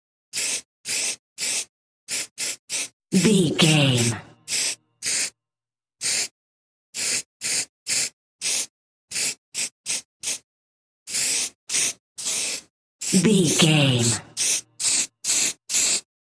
Graffiti aerosol spray short
Sound Effects
foley